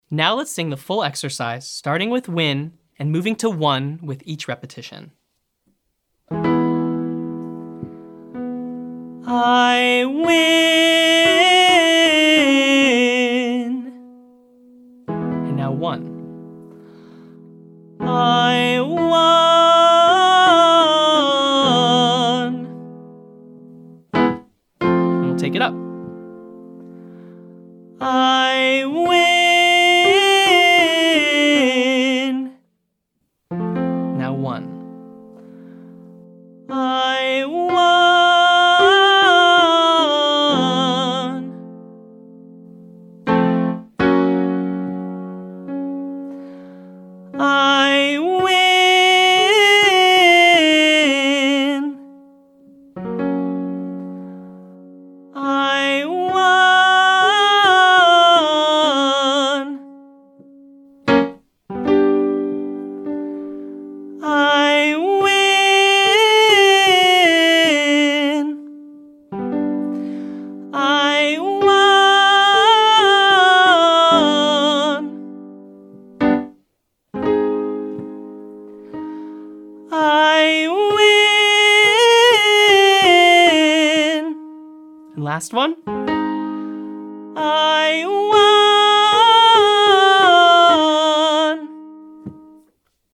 Keep the “Ih” vowel tall and narrow.
Now try opening up to Won but try to keep that same sensation you got from using Win.